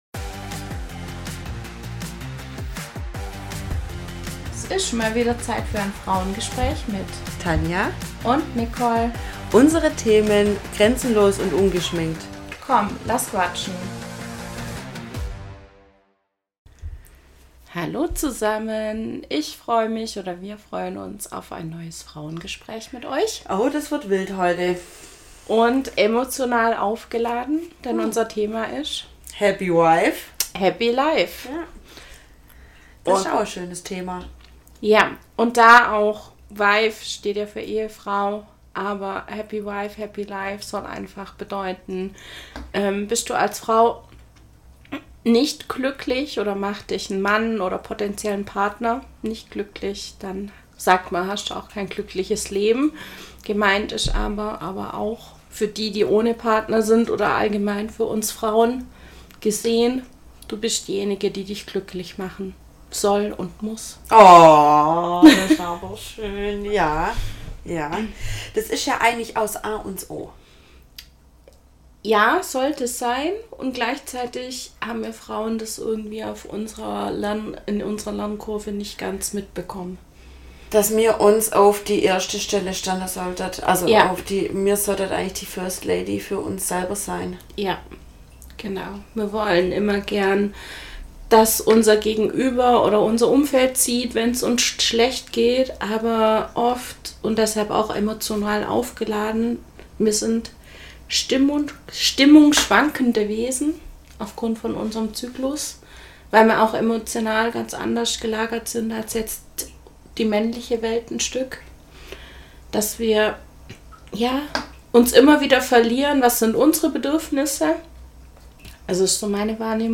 Wie haben mal wieder ein besonderes Frauengespräch für euch…!